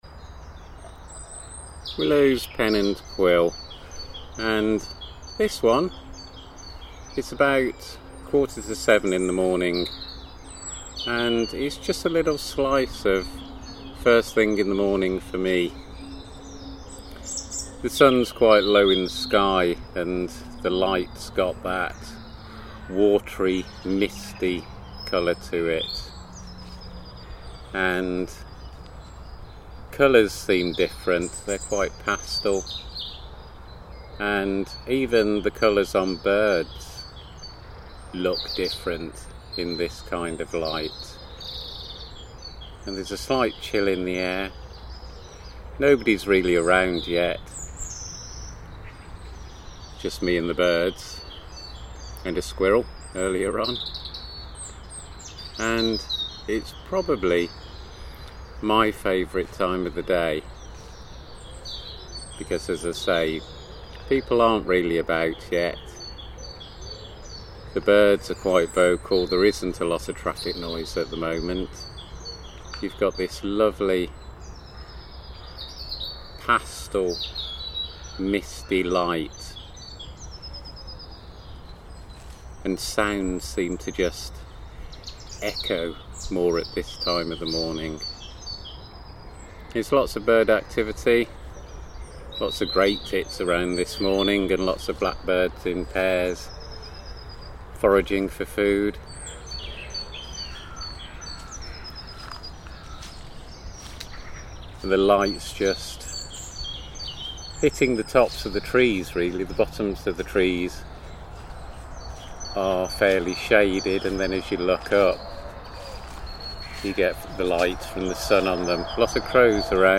10 minutes with me on the park just before 7am on a chilly but bright Spring morning. Watery light, Pastel colours, Bird song, Dog walkers and Frogs.